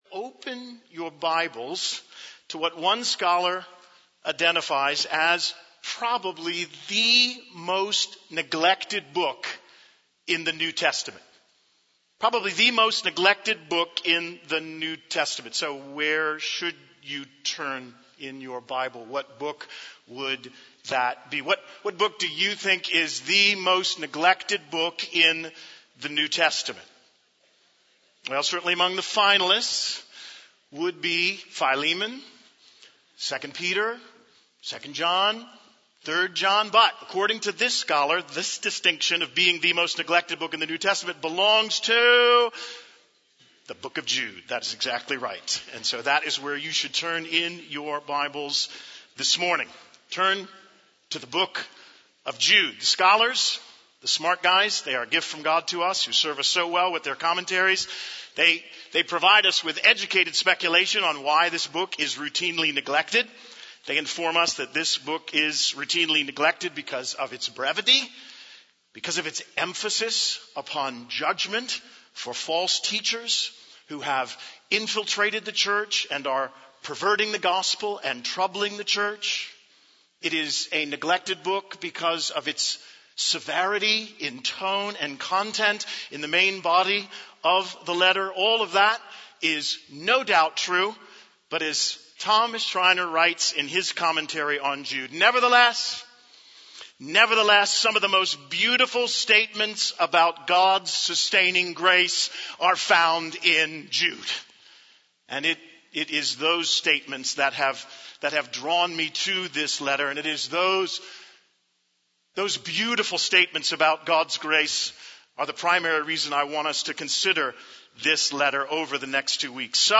In this sermon, the preacher emphasizes the importance of the opening and introduction of a particular book in the Bible.